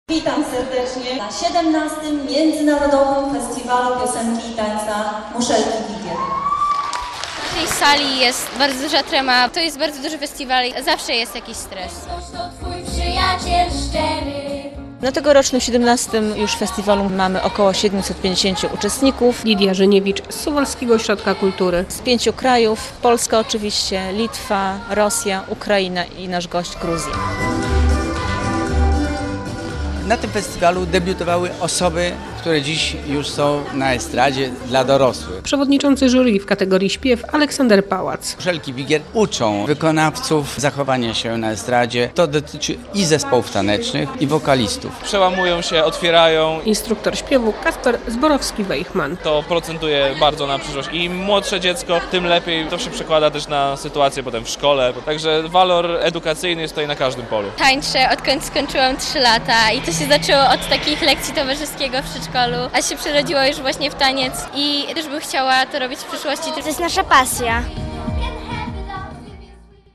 Od rana (26.05) w Suwalskim Ośrodku Kultury trwają przesłuchania uczestników Międzynarodowego Festiwalu Piosenki i Tańca "Muszelki Wigier".